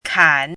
chinese-voice - 汉字语音库
kan3.mp3